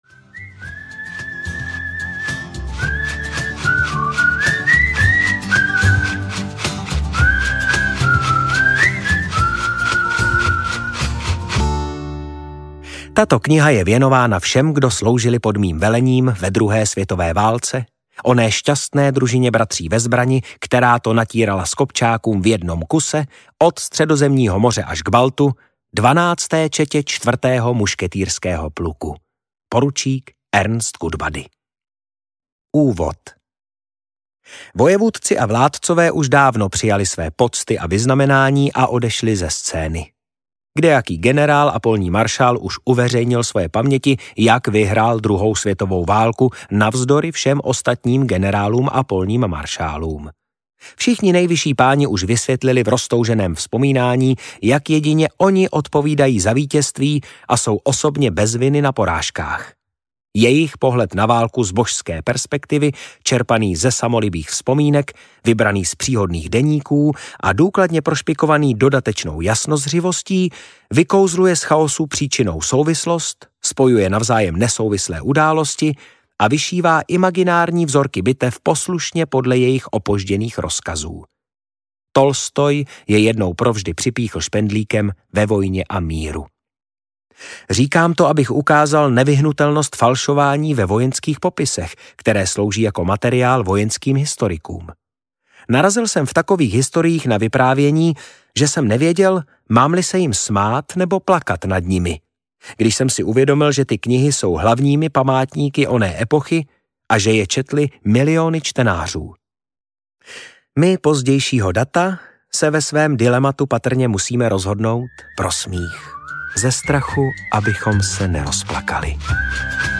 Jak jsem vyhrál válku audiokniha
• InterpretMartin Písařík